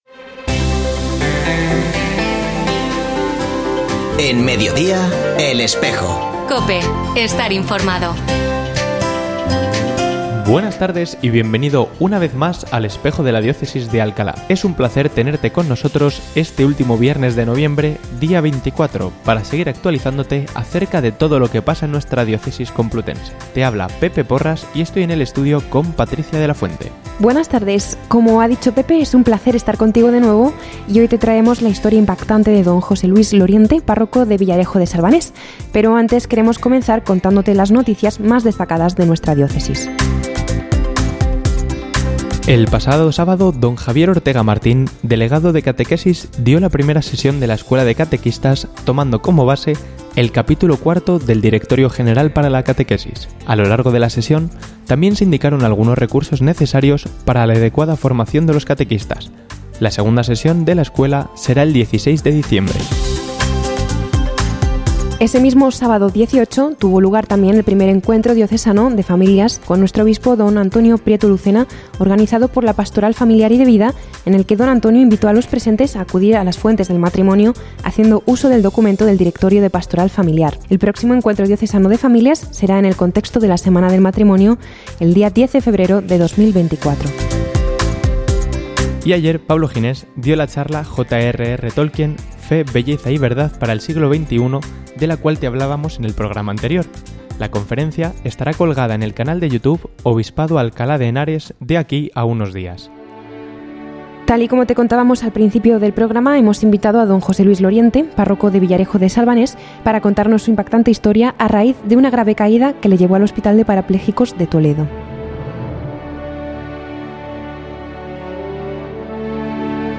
Ofrecemos el audio del programa de El Espejo de la Diócesis de Alcalá emitido hoy, 24 de noviembre de 2023, en radio COPE. Este espacio de información religiosa de nuestra diócesis puede escucharse en la frecuencia 92.0 FM, todos los viernes de 13.33 a 14 horas.